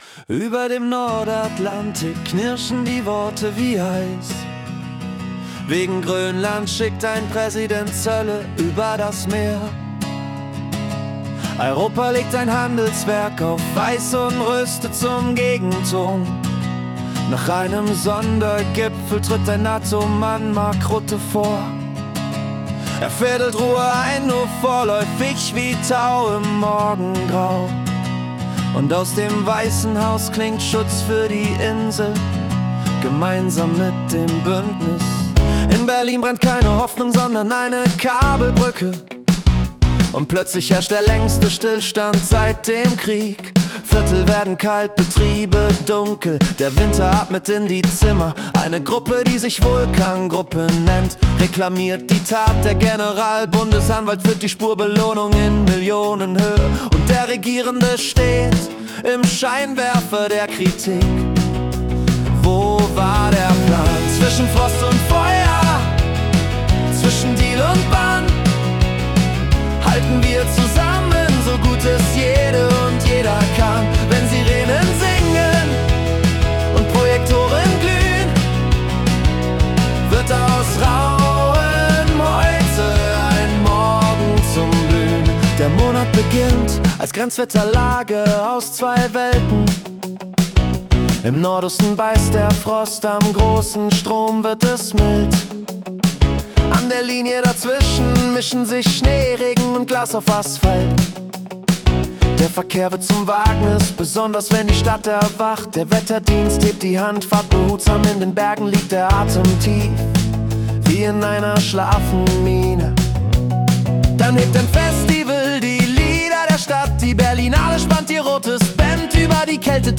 Februar 2026 als Singer-Songwriter-Song interpretiert.